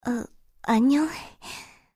slayer_f_voc_social_01.mp3